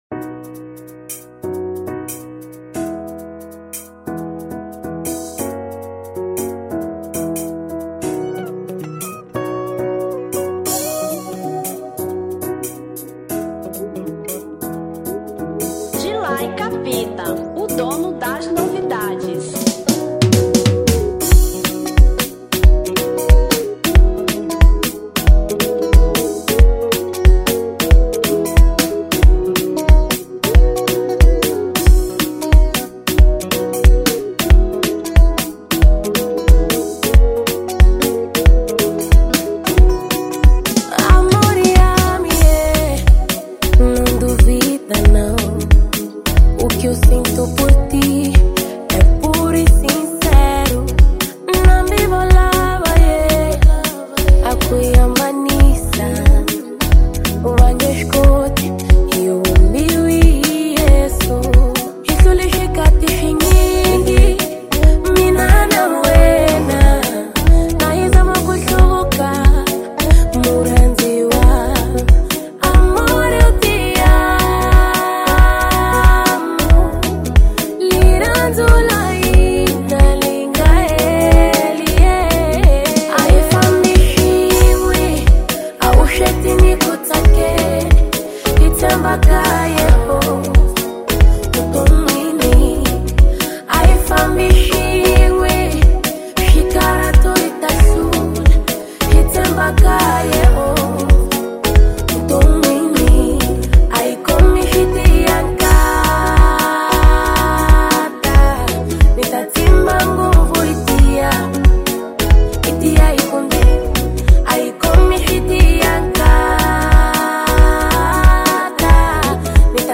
Marrabenta 2025